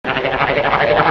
Play, download and share Trombone Gobble original sound button!!!!
trombone-gobble.mp3